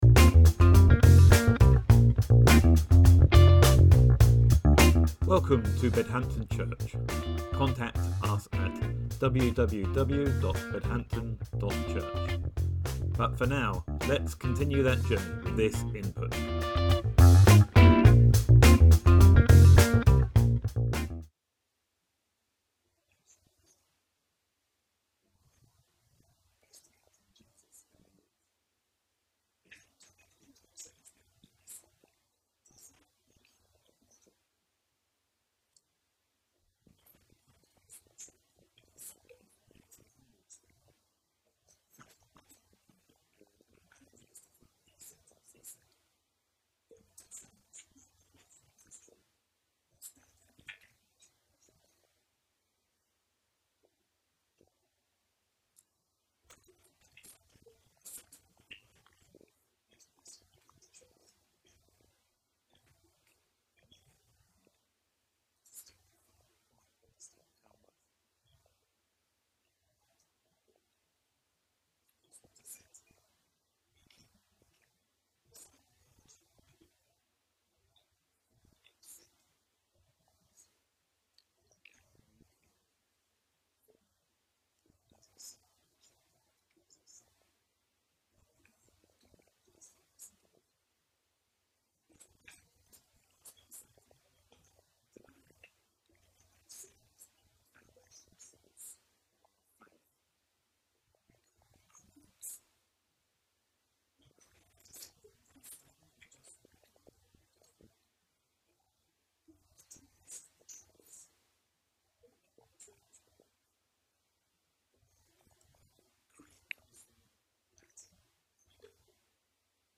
Sermon September 8th, 2024 - Creeds: I believe.